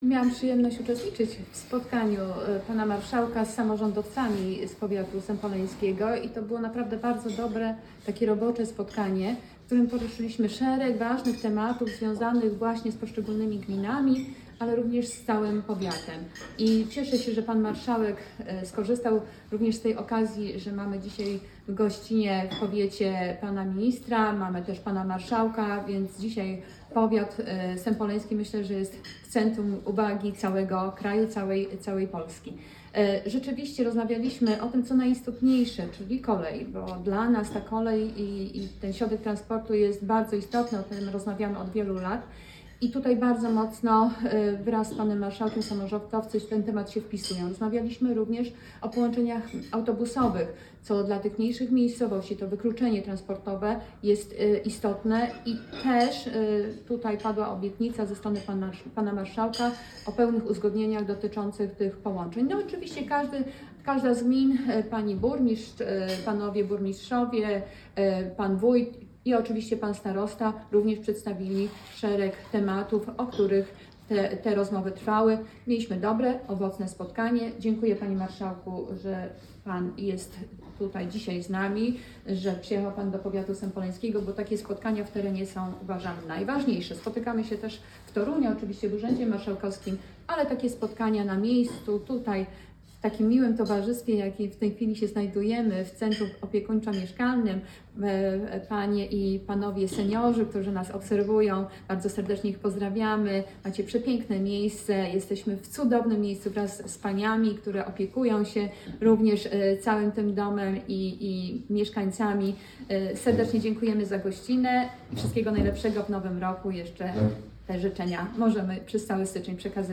Z wizytą gospodarską w Sępólnie Krajeńskim
Wystąpienie posłanki Iwony Kozłowskiej: